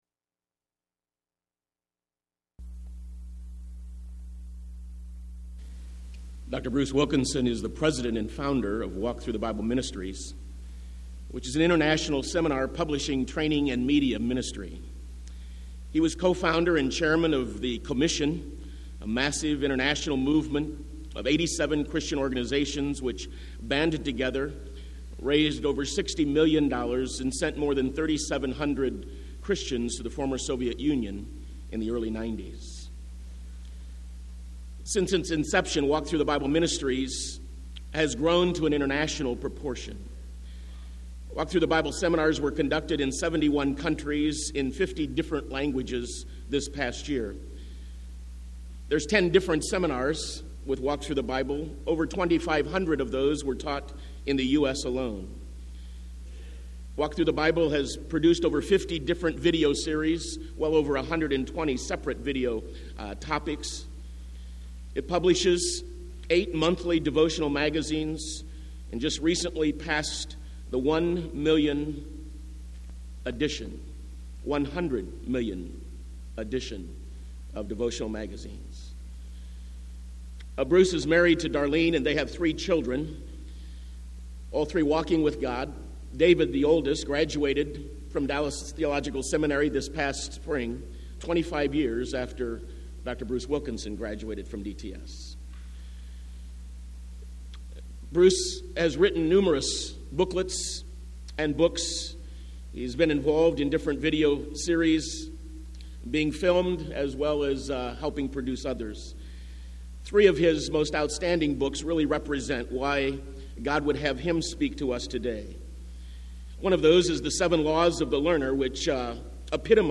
Bruce Wilkinson breaks down the core principles of visionary leadership at the 1999 DTS National Leadership Conference.